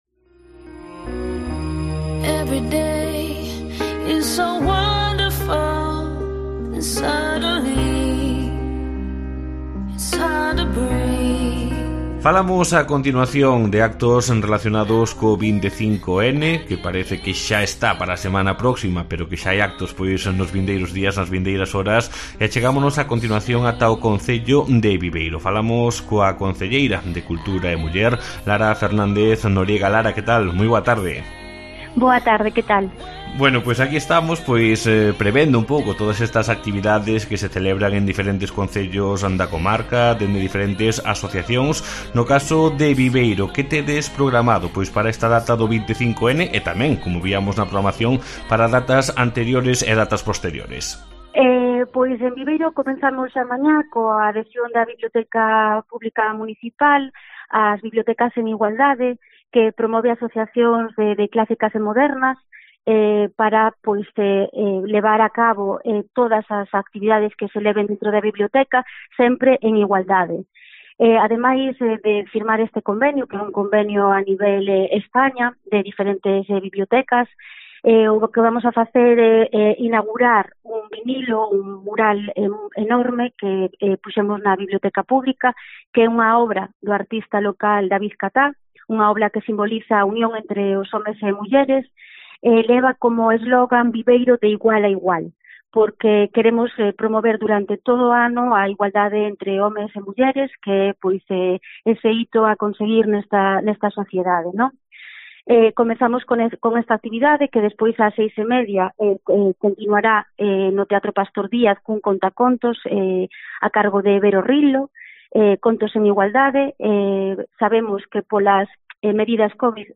La edil de Cultura e Muller, Lara Fernández-Noriega, habló en los micrófonos de COPE de la Costa